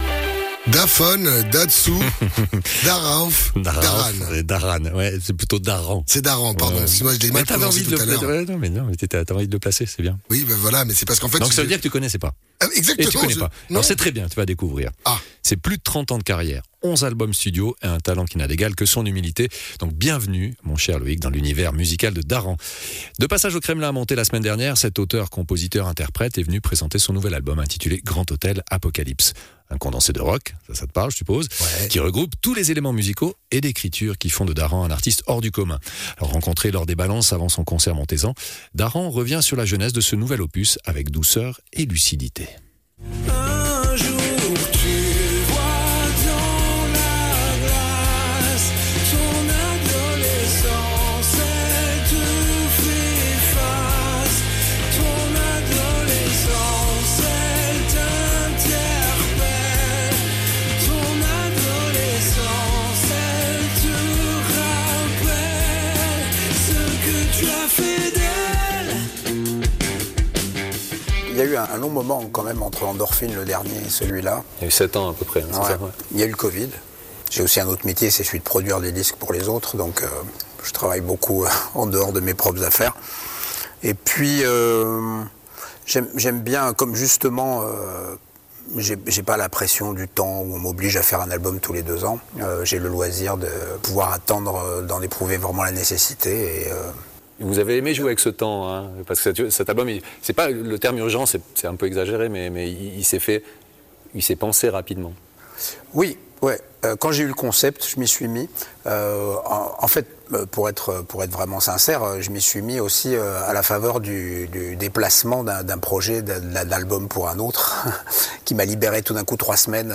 Intervenant(e) : Daran, auteur-compositeur et interprète